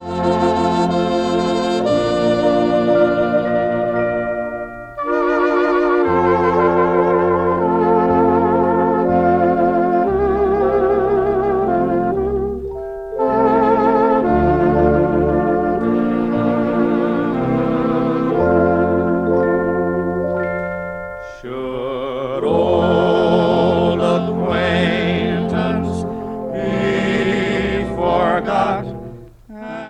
• New Age